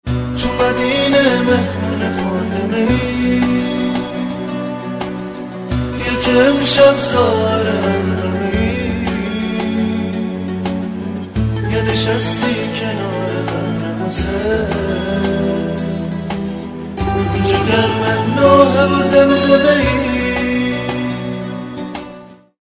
رینگتون احساسی و باکلام